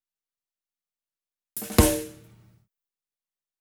16 rhdrm133snare.wav